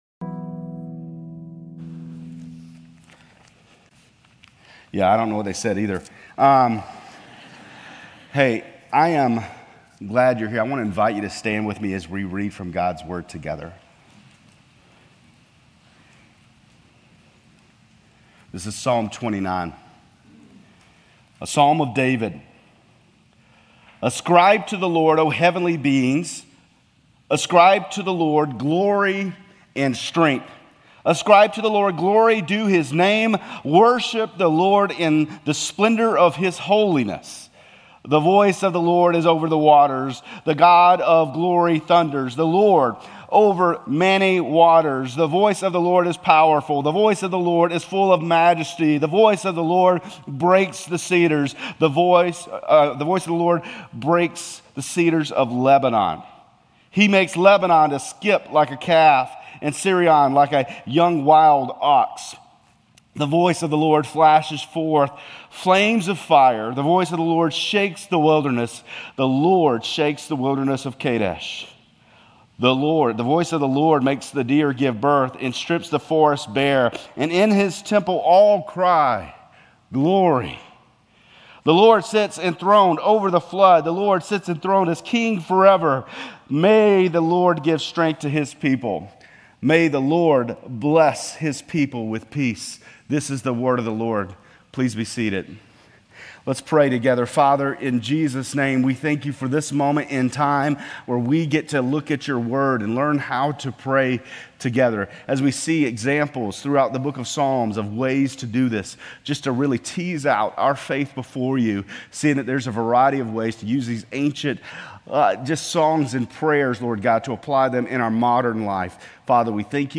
Grace Community Church Lindale Campus Sermons Psalm 29 - Praise Jun 23 2024 | 00:23:53 1x 00:00 / 00:23:53 Subscribe Share RSS Feed Share Link Embed